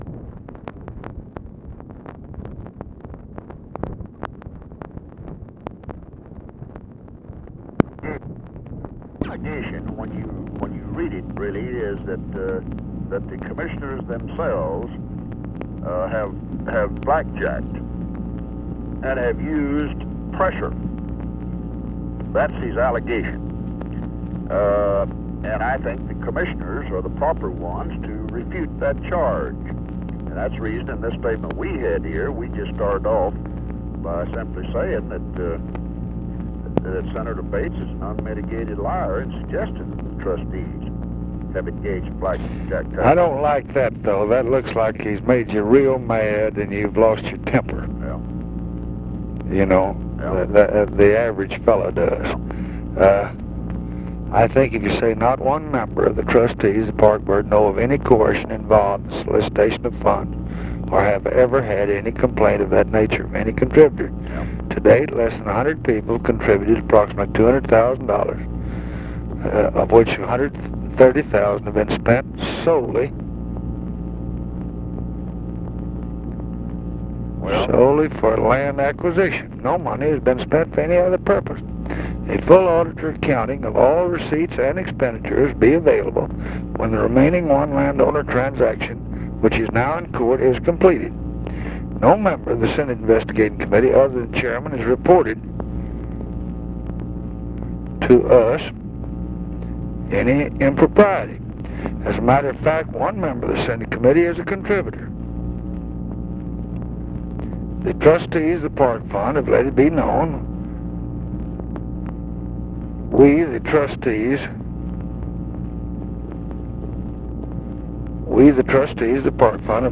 Conversation with JOHN CONNALLY, October 17, 1966
Secret White House Tapes